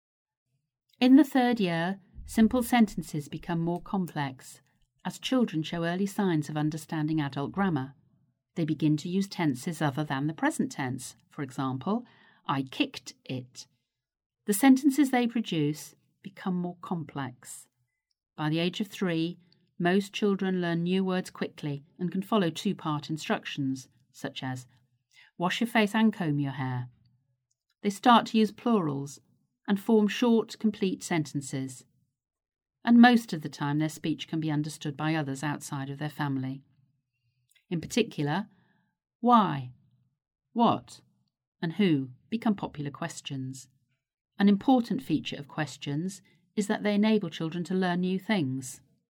Narration audio